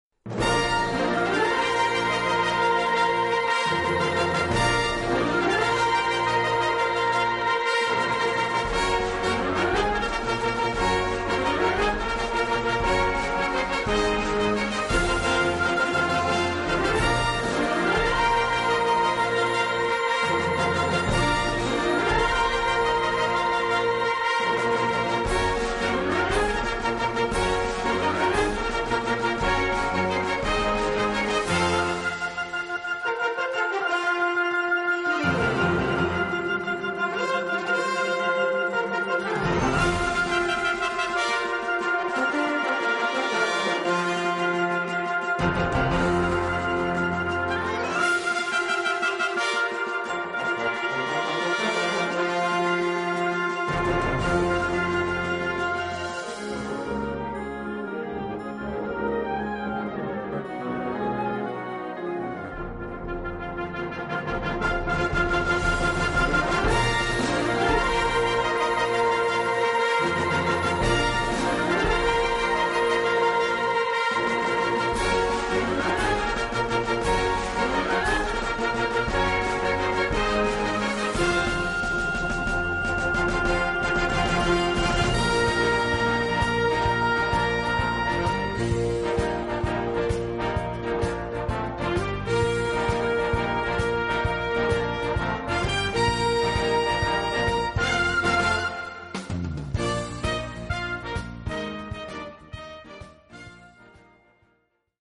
Gattung: Moderne Unterhaltungsmusik
Besetzung: Blasorchester